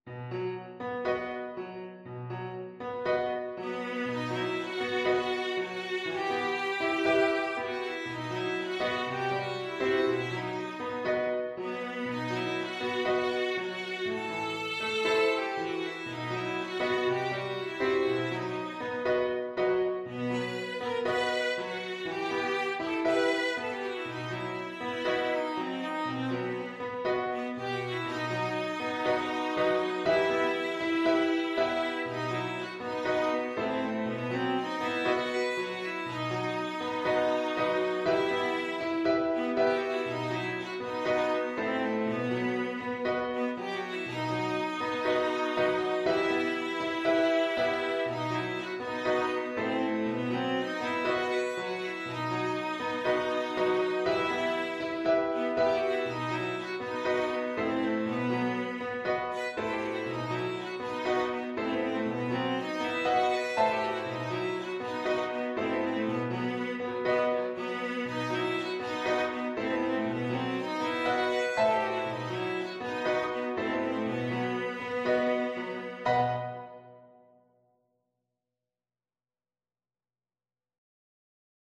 Viola
Traditional Music of unknown author.
E minor (Sounding Pitch) (View more E minor Music for Viola )
Allegro moderato =120 (View more music marked Allegro)
4/4 (View more 4/4 Music)